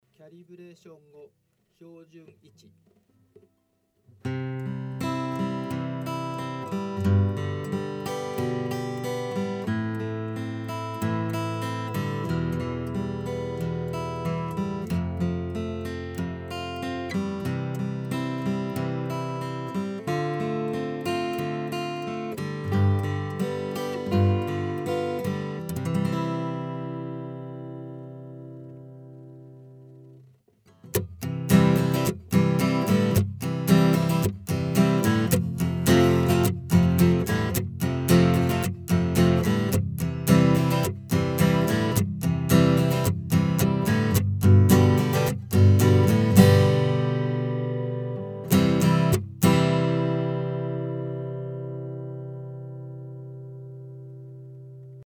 直接オーディオIFに入れてエフェクト類は全くかけていません。
11F,12Fはきちんと音が出ていないけど、こんなんで良いのか？？
ところがびっくり、これは行ける！このプリアンプ結構すごい
キャブレーション後（NATURAL